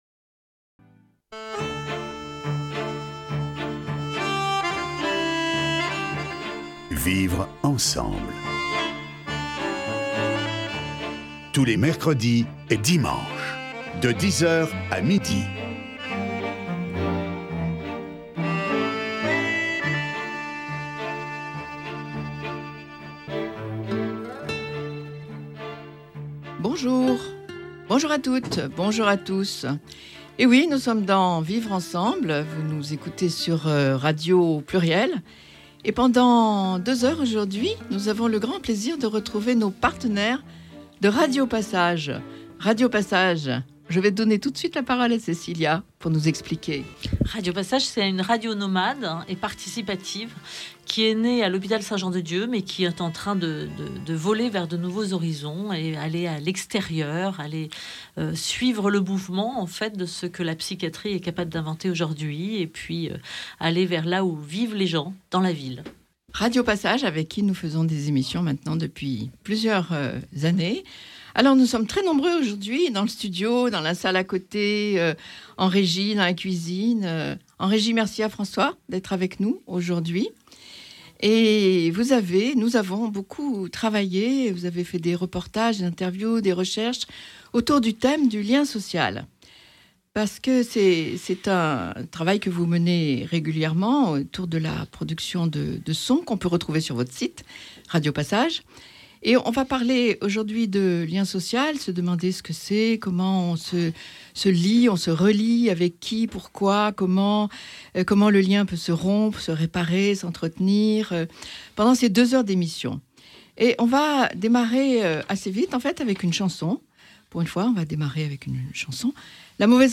Emission Vivre ensemble du 8 octobre 2025 (première partie)